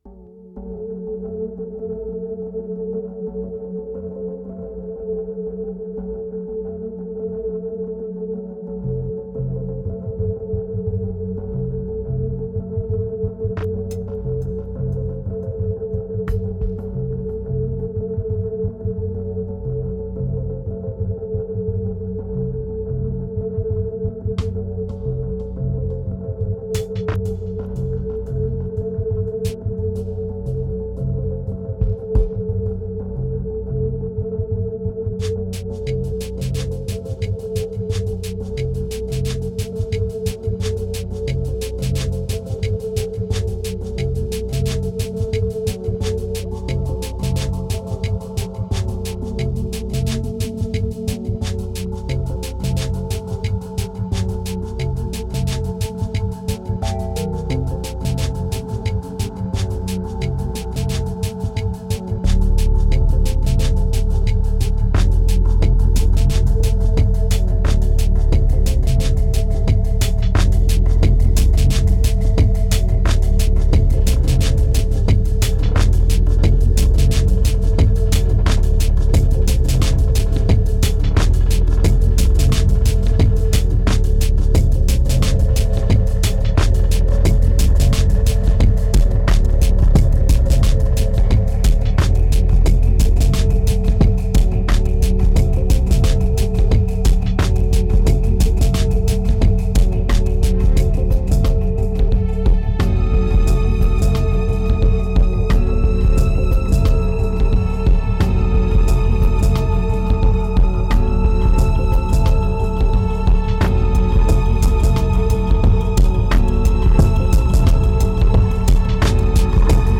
2398📈 - 34%🤔 - 89BPM🔊 - 2011-01-27📅 - -40🌟